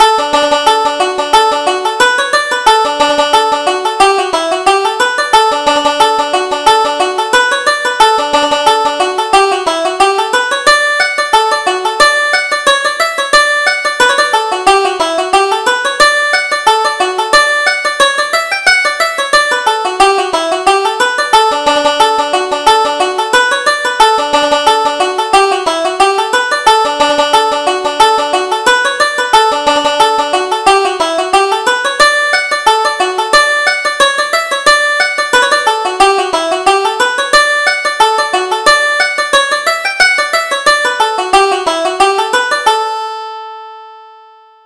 Reel: The Callan Lasses